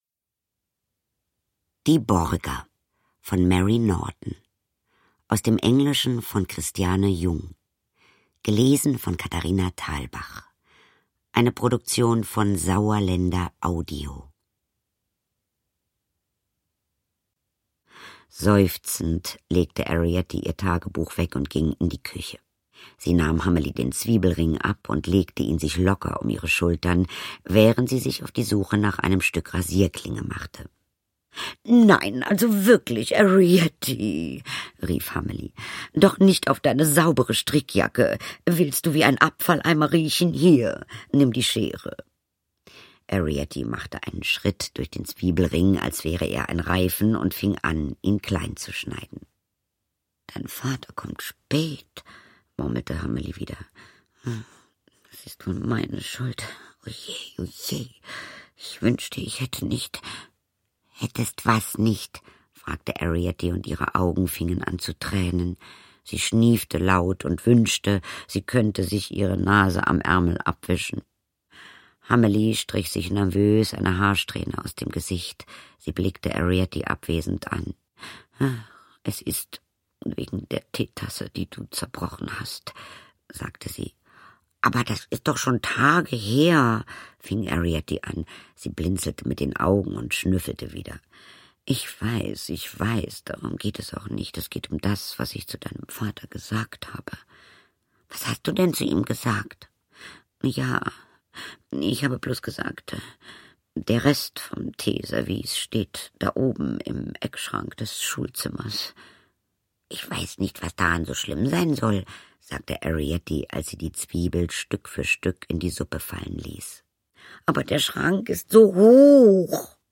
Katharina Thalbach (Sprecher)
2015 | 2. Auflage, Ungekürzte Ausgabe
Katharina Thalbach fängt jeden noch so kleinen Schritt der Winzlinge mit ihrer unnachahmlichen Stimme ein.